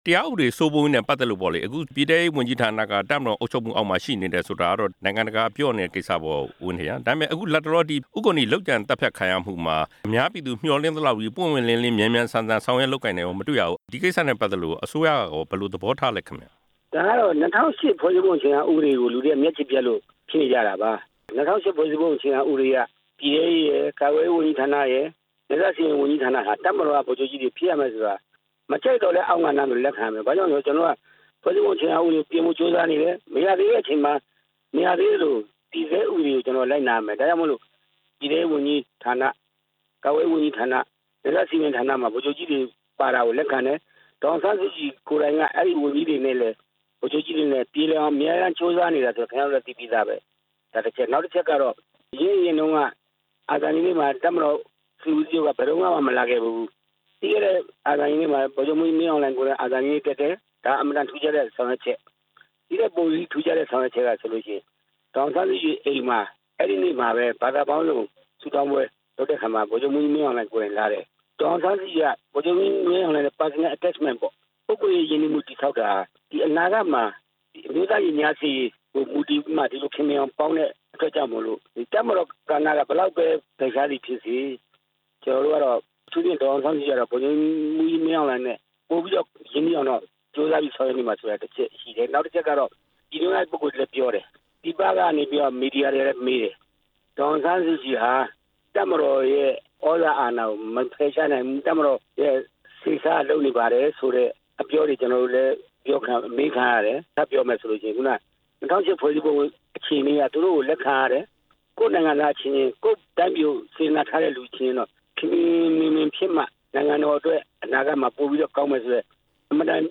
အရပ်သားအစိုးရ တစ်နှစ်ပြည့်အဖြစ် RFA က ဆက်သွယ်မေးမြန်းရာမှာ သူက အခုလို ဖြေကြားခဲ့တာပါ။ NLD အနေနဲ့ လာမယ့် ကြားဖြတ်ရွေးကောက်ပွဲမှာ ပြီးခဲ့တဲ့ ၂ဝ၁၅ လိုပဲ နေရာအားလုံး အနိုင်ရဖို့ မျှော်မှန်းထားပေမယ့် ရှမ်းပြည်နယ်က တချို့နယ်မြေတွေမှာ NLD မဲဆွယ်လို့မရအောင် အဖွဲ့အစည်းတစ်ခုက ဟန့်တားပိတ် ပင်နေကြောင်းလည်း ဦးဝင်းထိန်က ပြောပါတယ်။